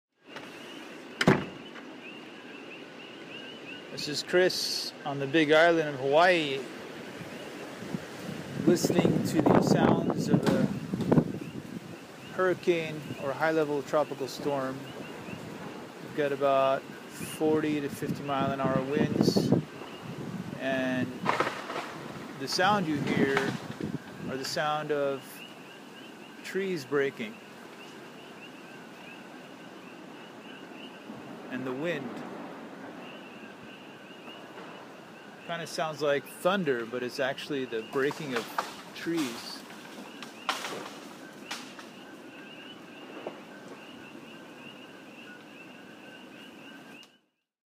BBC World Service - World Update Soundscapes project